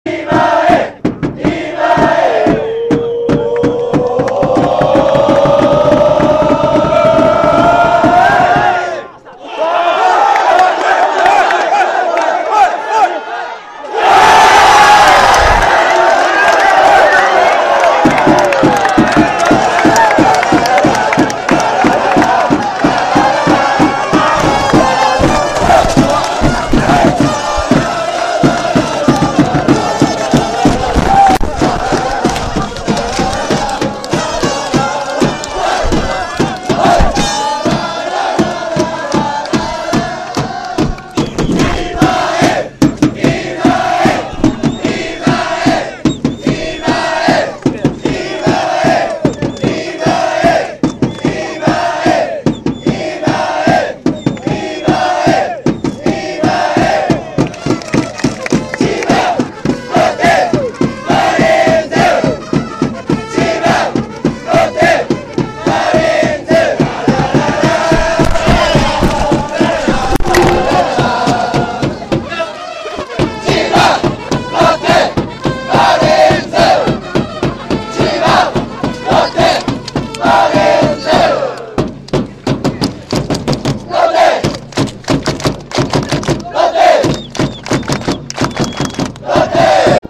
このページは２００９年までの応援歌の記録です。